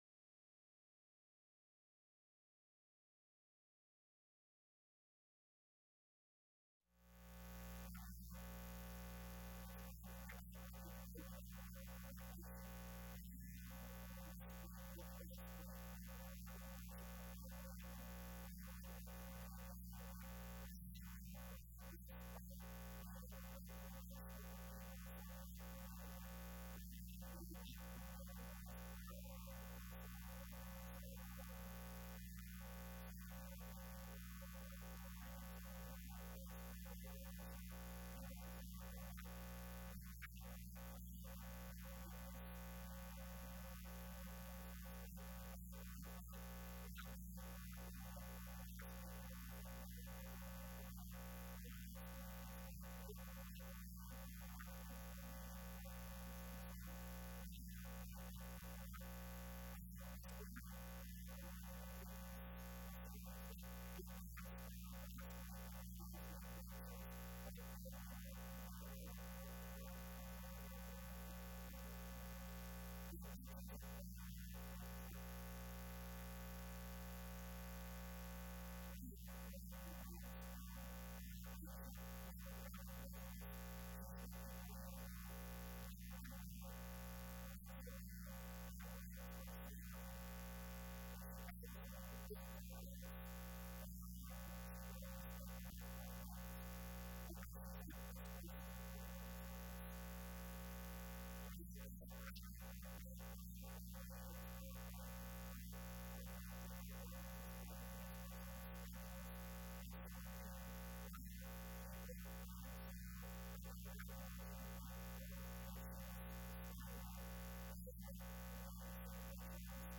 Communicating in a Tech-Driven World – Sermon 2